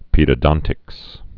(pēdə-dŏntĭks)